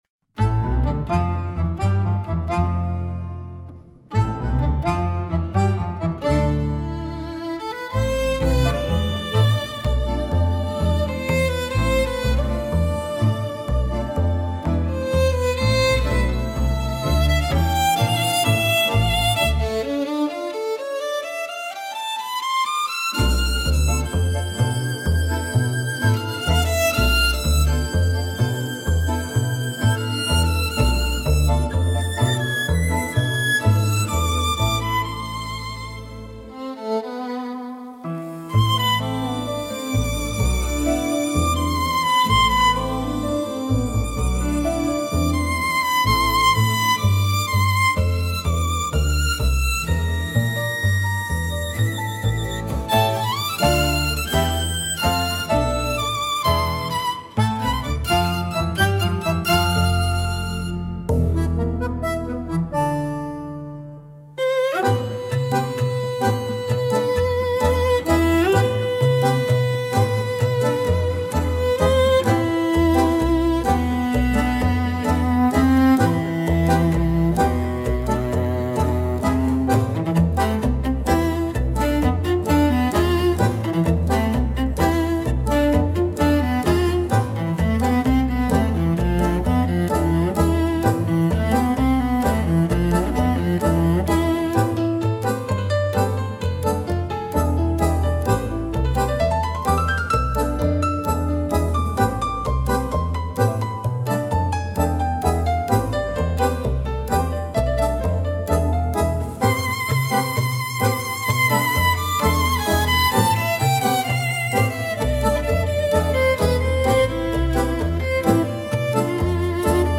música, arranjo: IA) (instrumental)